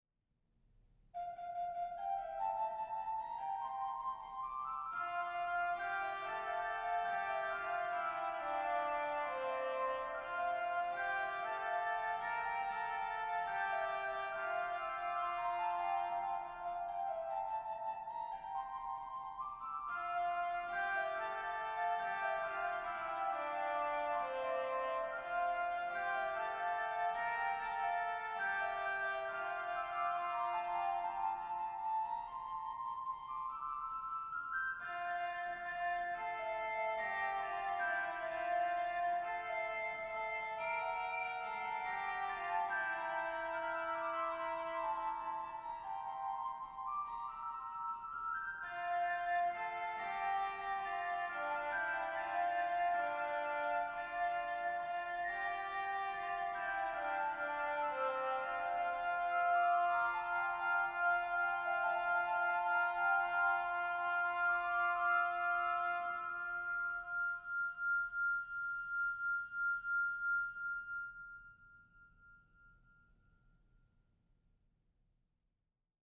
Voicing: Organ Collection